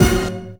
SWINGSTAB 4.wav